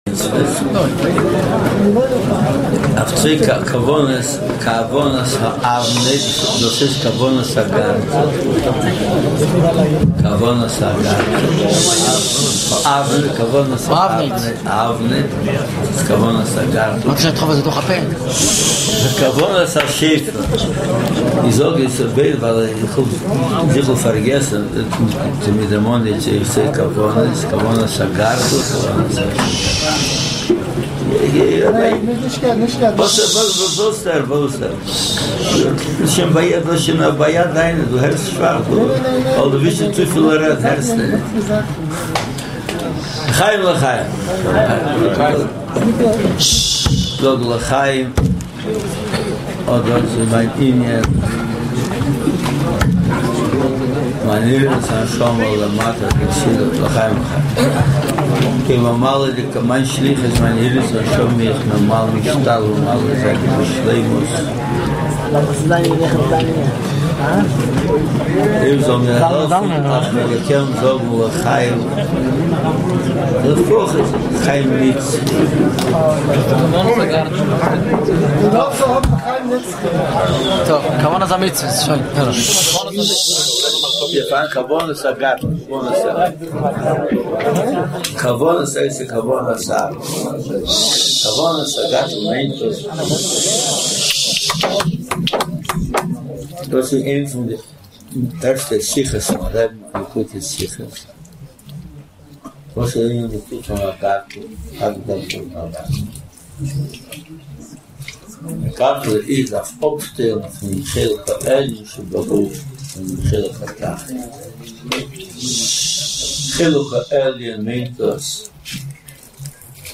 התוועדות חג הגאולה יב יג תמוז חלק ד שנת תשעה